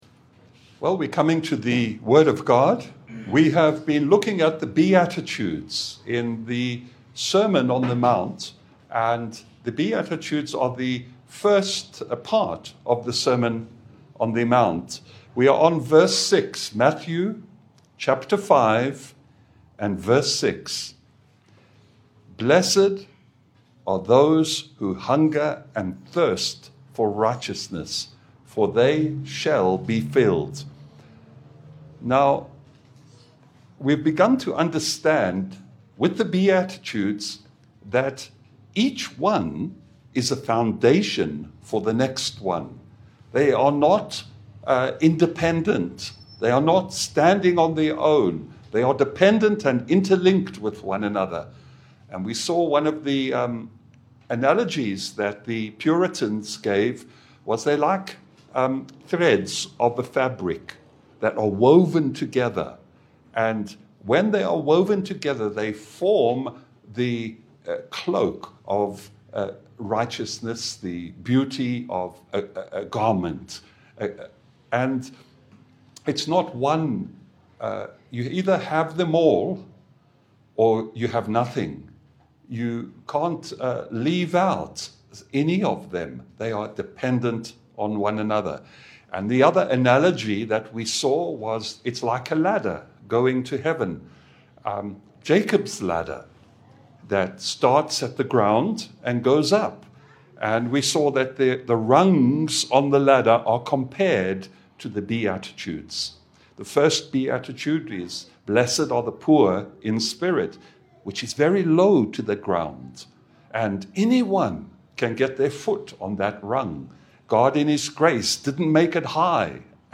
Morning preaching from Nanyuki Reformed Fellowship Kenya at Kirimara Springs Hotel from our series the Beatitudes
Service Type: Sunday Bible fellowship